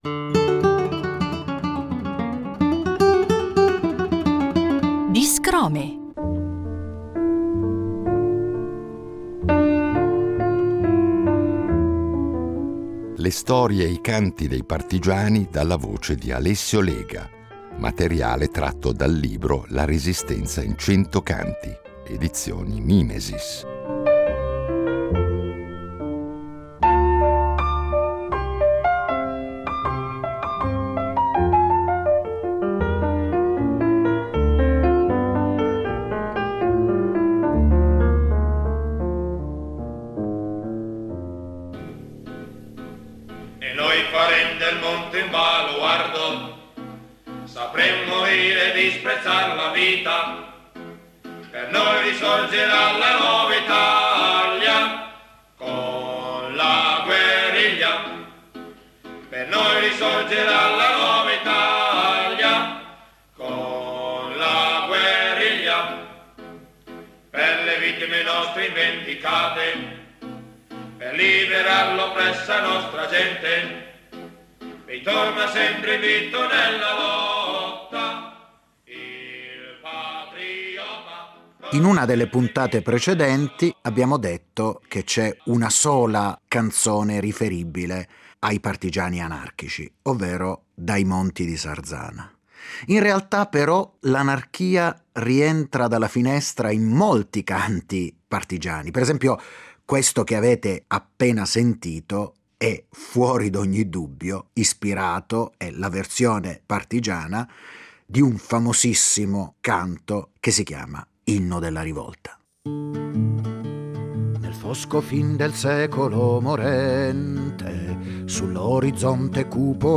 Le storie e le canzoni della Resistenza dalla voce e dalla chitarra